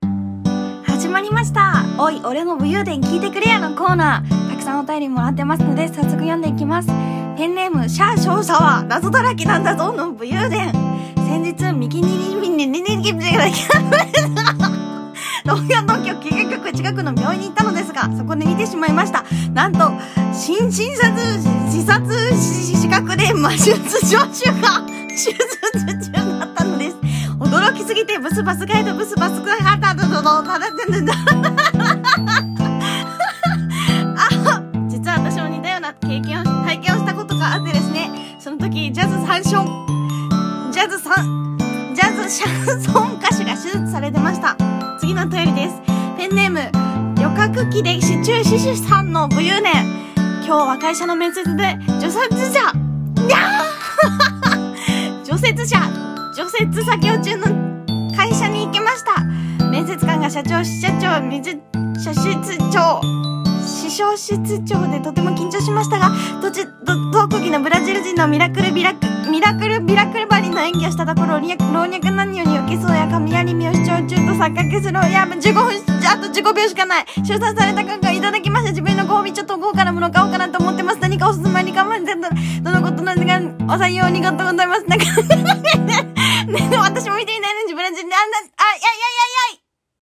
こんなラジオは嫌だ【早口言葉】